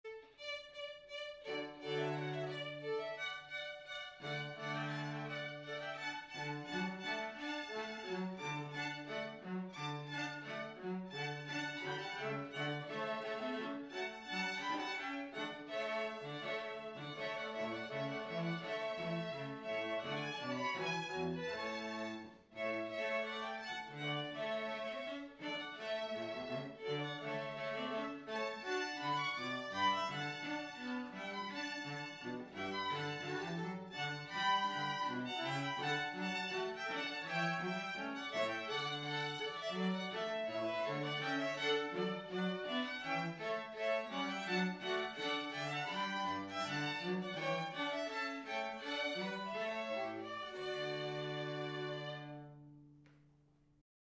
We have made a home recording of some snippets of the most popular Processionals and Recessionals as an audio aid in making your selections.